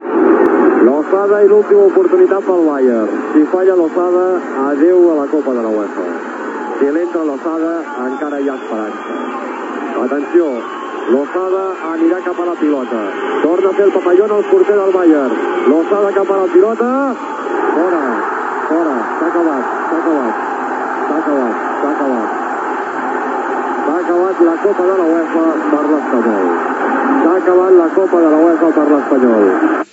Transmissió des del Bay Arena, de Leverkusen (Alemanya) del partit de tornada de la final de la Copa de le UEFA de futbol masculí entre el R.C.D.Espanyol i el Bayer Leverkusen. Narració del llançament del penal decisiu, en la tanda de desempat: Sebastián Losada l'errar i fa que guanyi l'equip alemany.
Esportiu